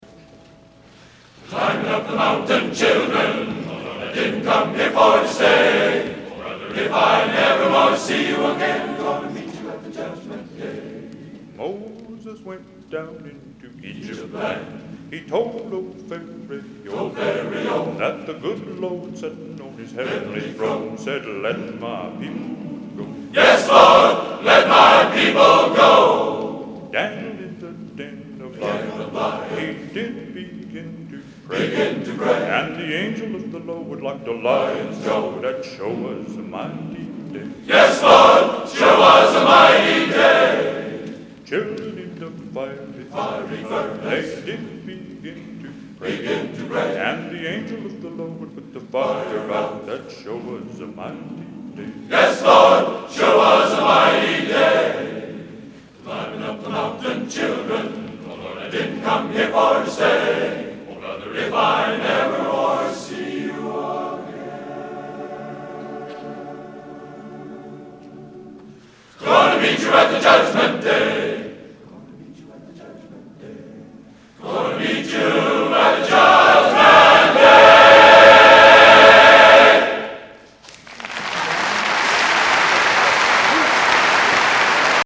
Location: West Lafayette, Indiana
Genre: Spiritual | Type: Featuring Hall of Famer